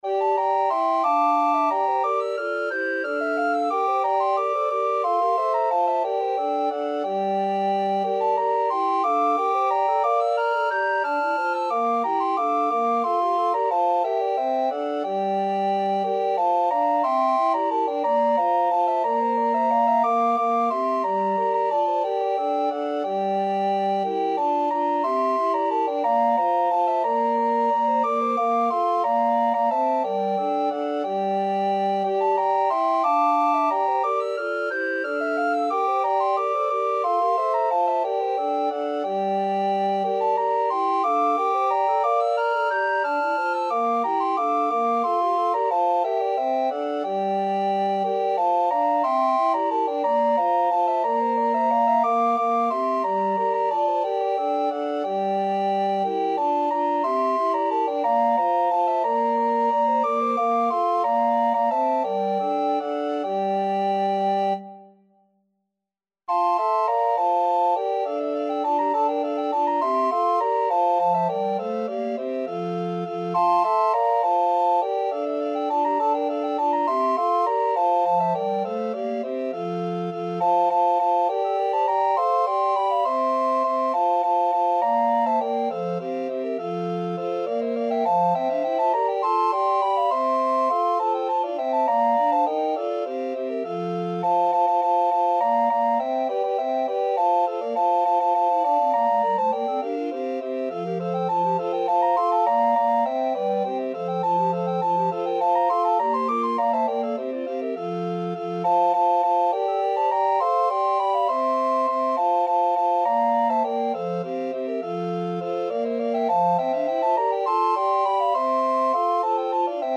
Arrangements of Renaissance, Baroque and Traditional Music for Recorders
These are 2 delightful Renaissance dances
set for SATB recorders.